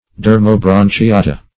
Search Result for " dermobranchiata" : The Collaborative International Dictionary of English v.0.48: Dermobranchiata \Der`mo*bran`chi*a"ta\, n. pl.